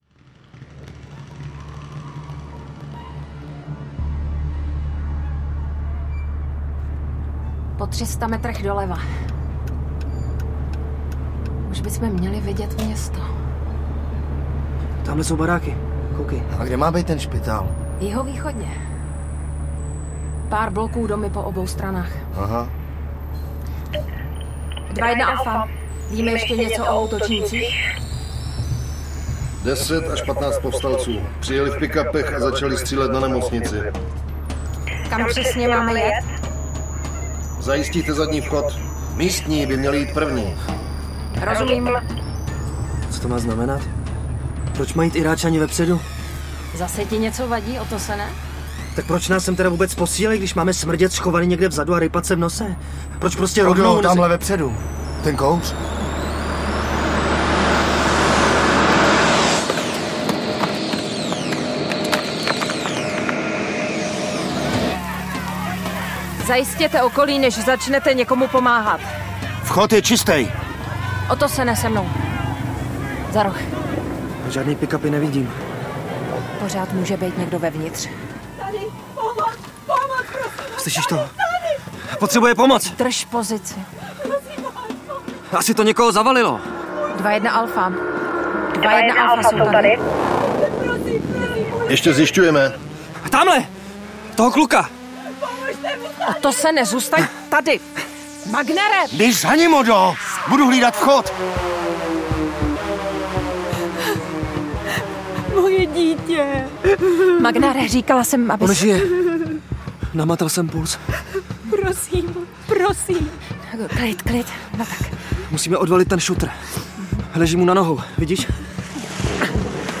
MADE IN NORWAY audiokniha
Ukázka z knihy